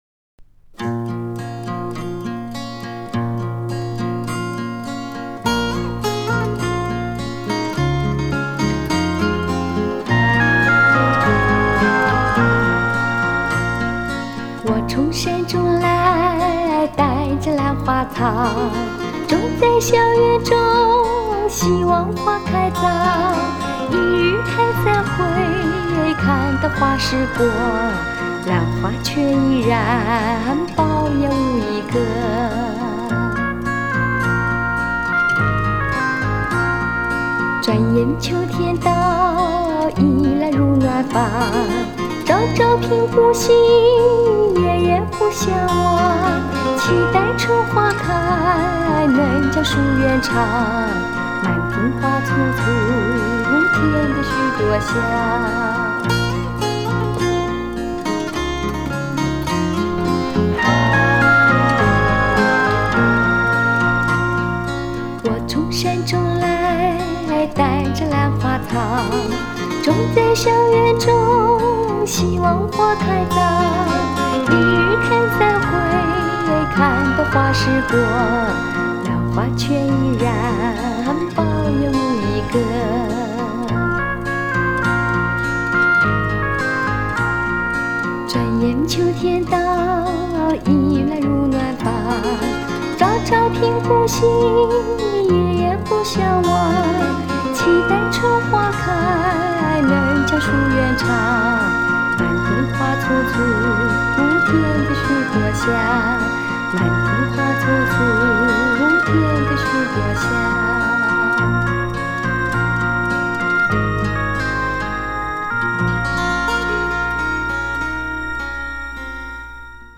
唱略带民歌风格的抒情歌曲
歌声甜美细致